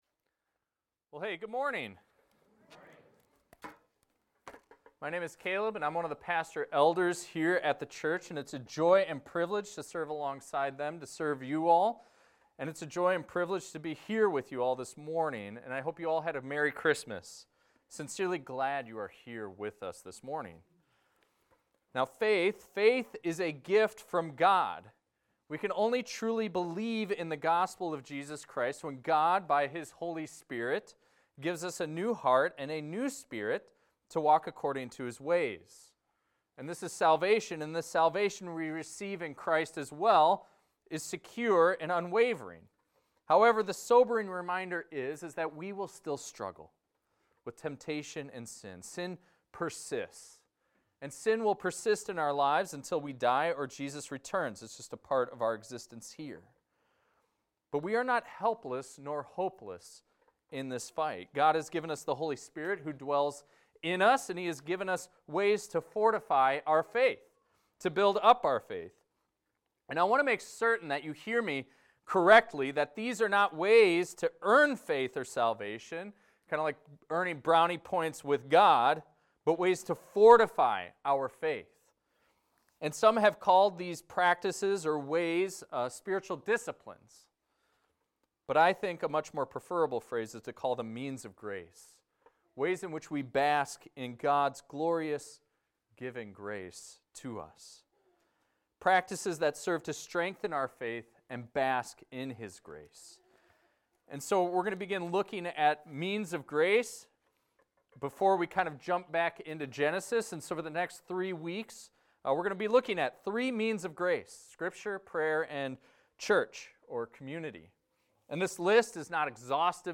This is a recording of a sermon titled, "Read the Word."